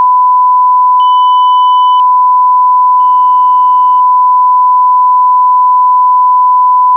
I inserted a 3 KHz 3rd harmonic tone that sustains for one second at 10 percent (-23 dB), then at 1 percent (-43 dB), then at 0.1 percent (-63 dB). Anyone can hear when there's 10 percent THD, but 1 percent is difficult to notice, and 0.1 percent is probably impossible for anyone to hear no matter how good their ears are. The image below shows how the added distortion tone pulses on and off, just so it's perfectly clear what the file contains.
thd_test.wav